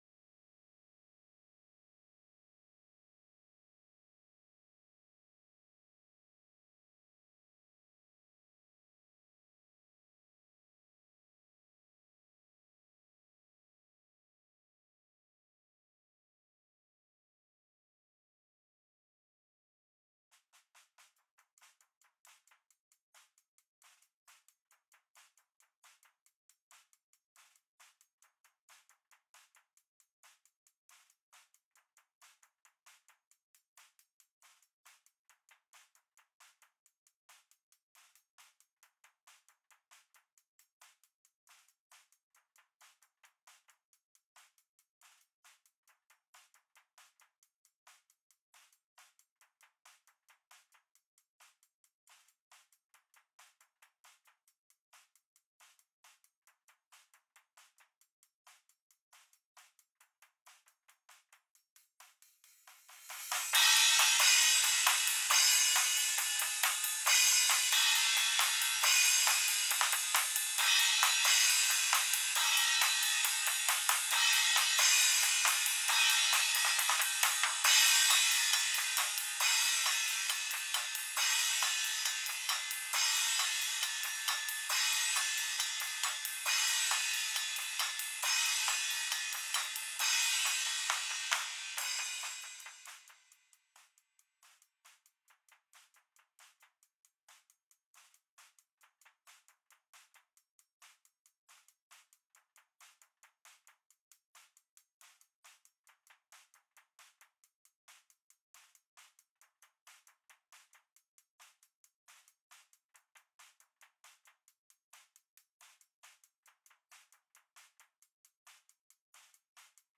Faith - Reaper Remix_freeze_Faith_Overheads-001.wav